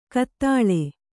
♪ kattāḷe